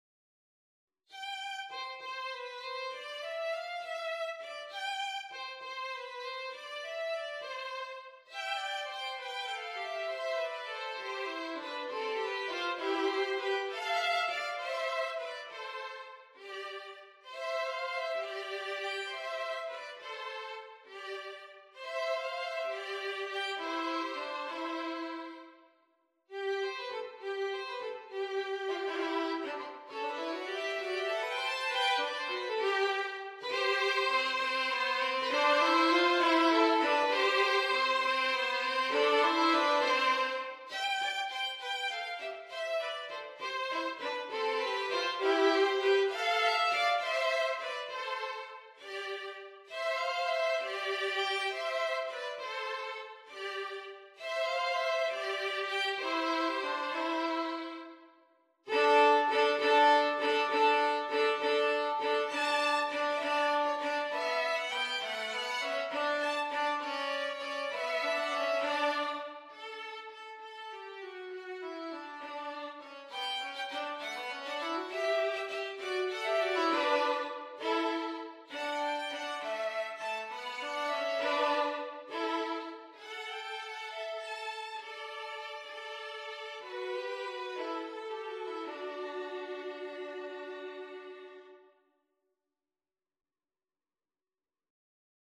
a French carol arranged for violin duet